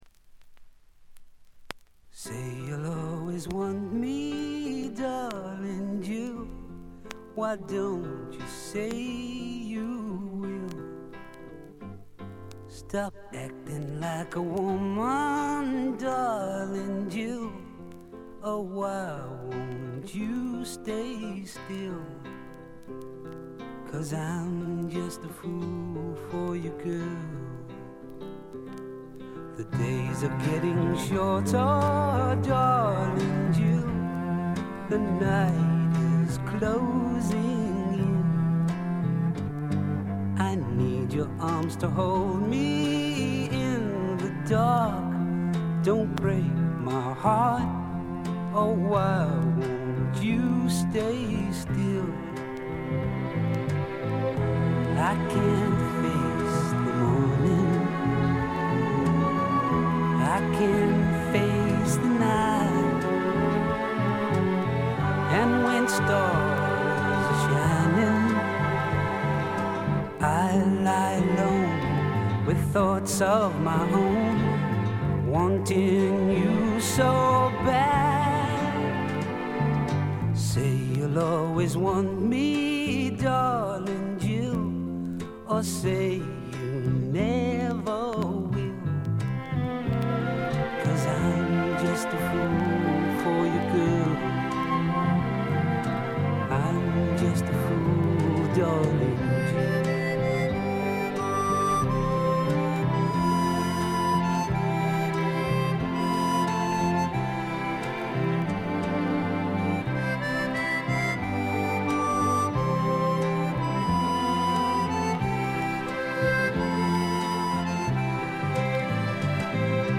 ところどころでチリプチ。
ソフトサイケ、ドリーミーポップの名作。
試聴曲は現品からの取り込み音源です。